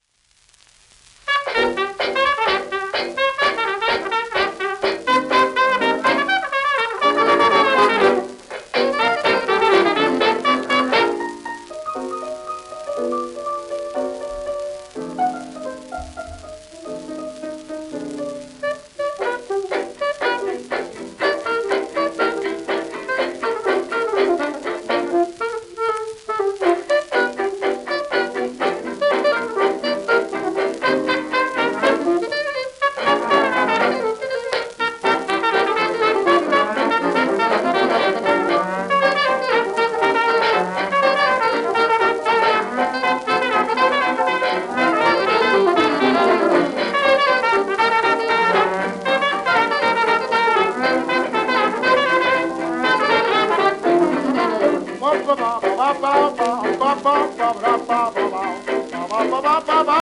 1928年頃の録音